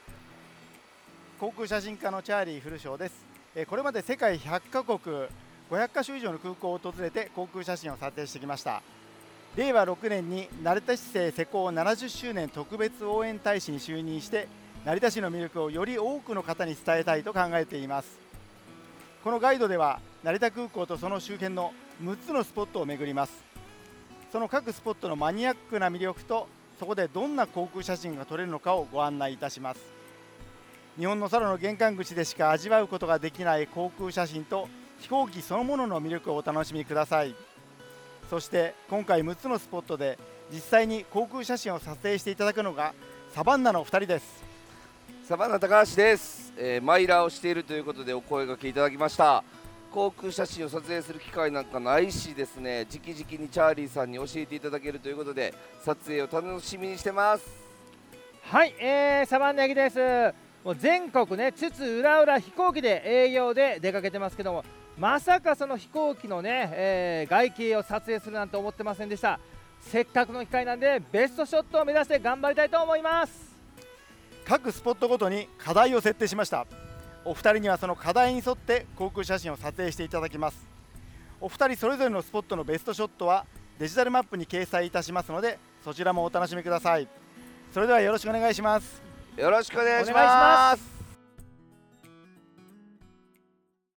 撮影中の様子では飛行機の音なども収録され、臨場感あふれる音声をお楽しみください。
音声ガイドツアーの詳細